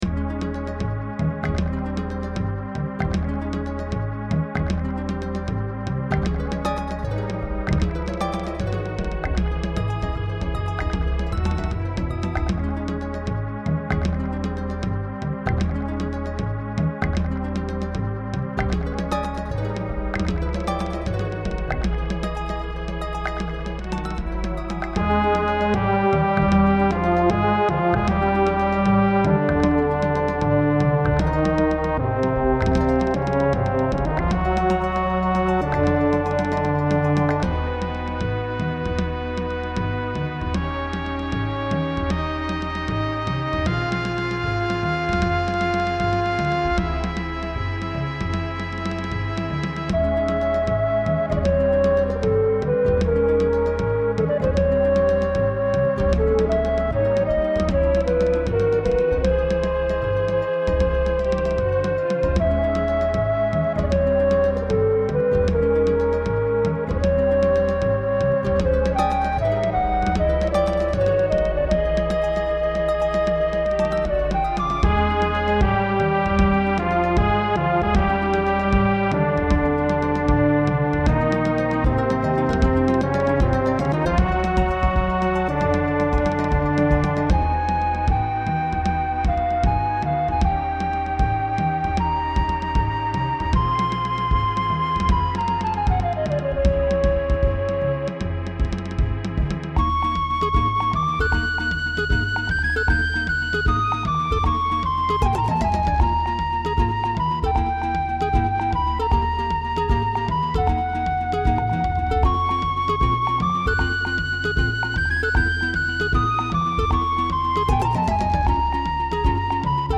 This is SNES era RPG music. It has a flute on the main melody, with acoustic bass, guitar, strings and horns for background. It's for contemplative, unsetlling revelations, or maybe a desert themed level. The track has a shift in intensy towards the end before it loops back.
There's also a mono version, since the original SNES stereo effects used can make a mono playback sound worse.
snes_disturbing_developments_mono.mp3